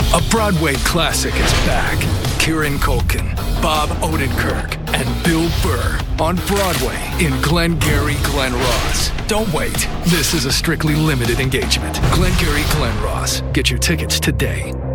Commercials Download This Spot